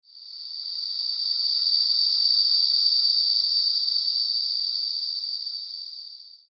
Processed Air Release